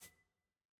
sfx-jfe-ui-toggle-hover.ogg